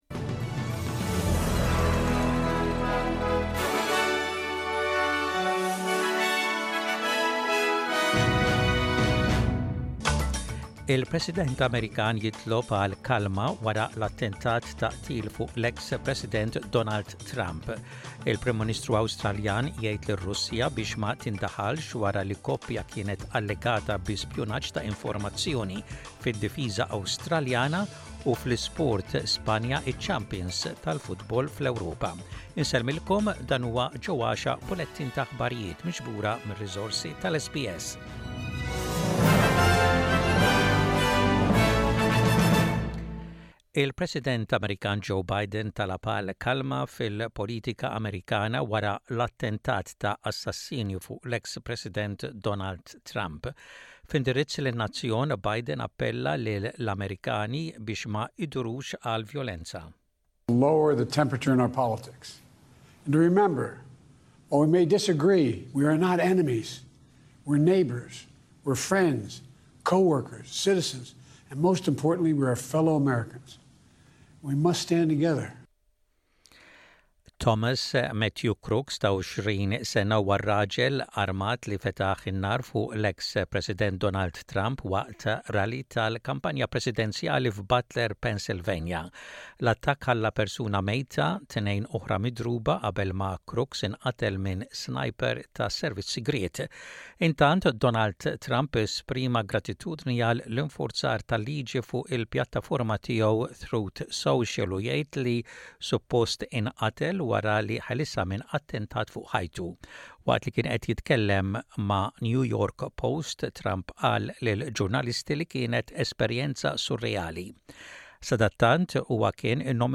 SBS Radio | Aħbarijiet bil-Malti: 16.07.24